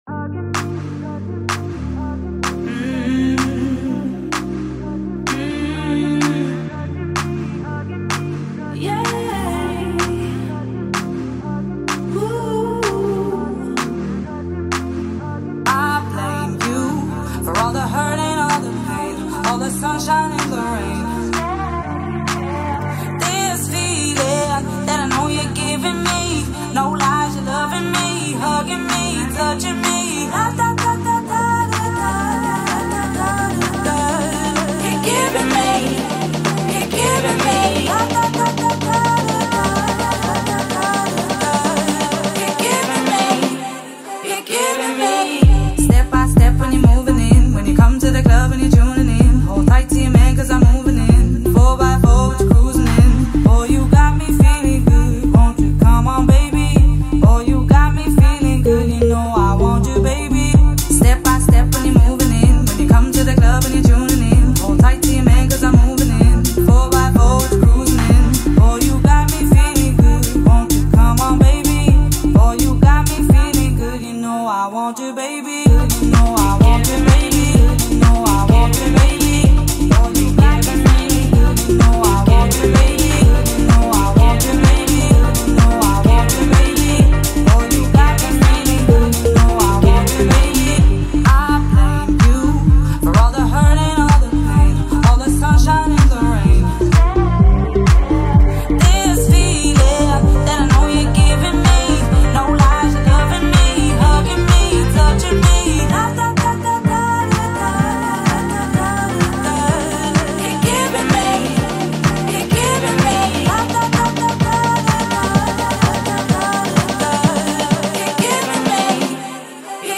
Irish dance vocalist